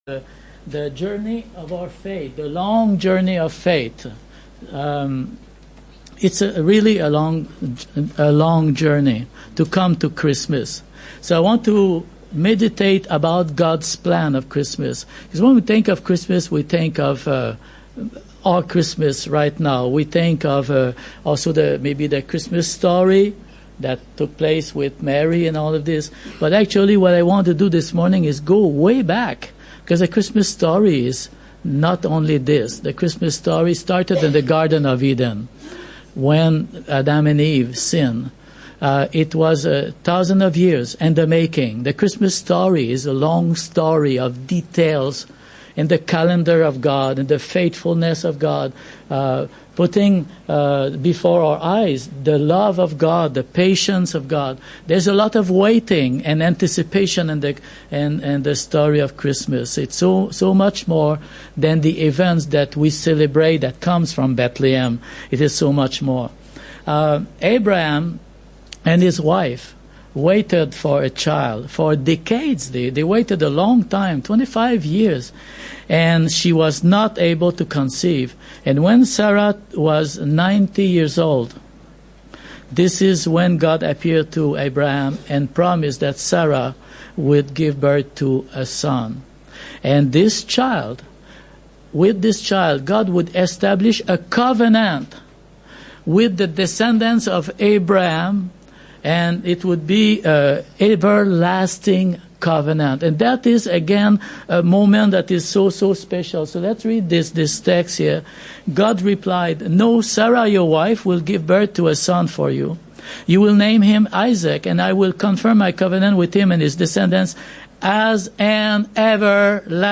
Meditation on the promise of Christmas